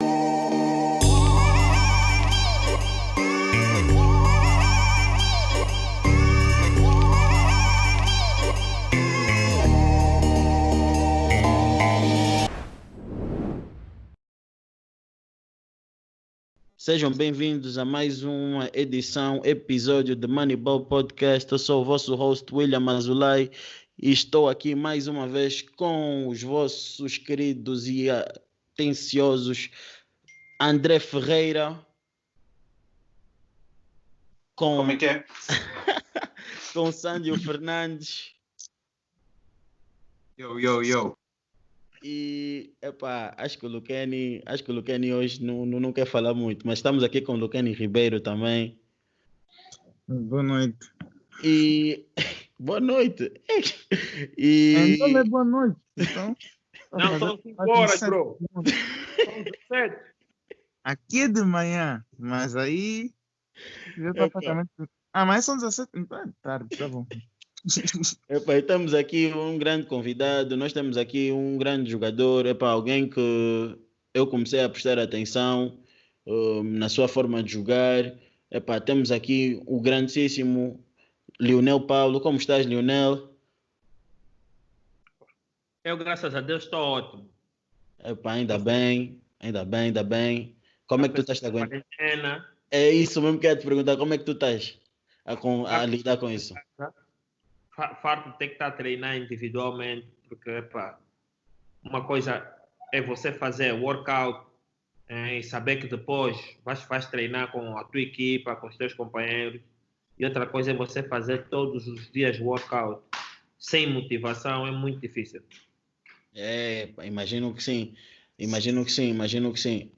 ENTREVISTAS MONEYBALL